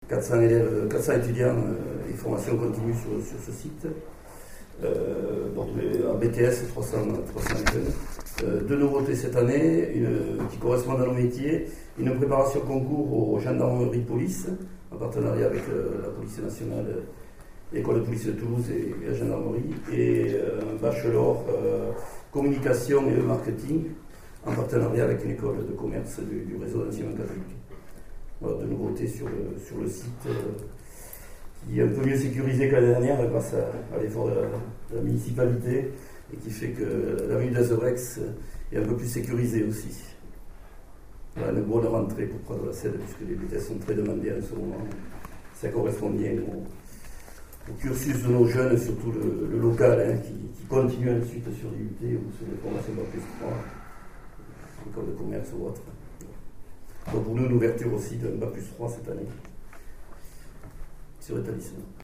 Chaque responsable d’établissement est ensuite intervenu.
Les interventions